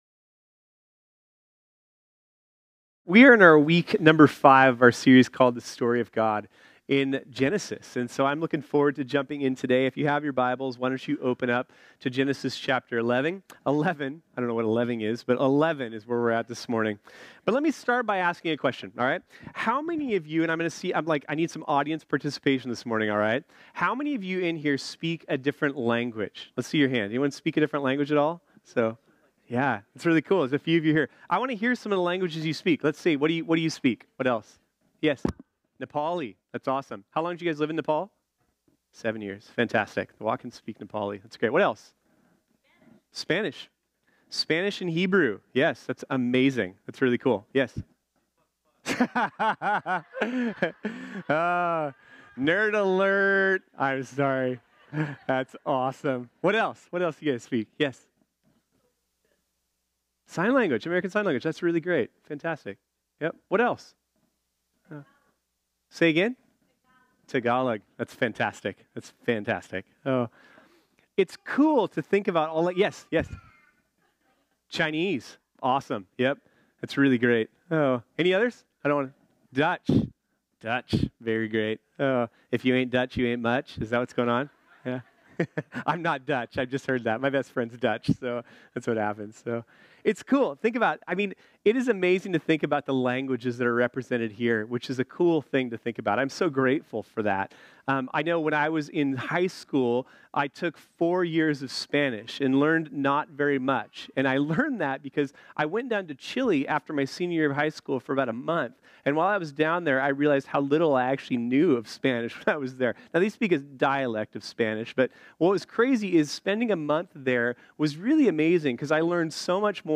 This sermon was originally preached on Sunday, February 3, 2019.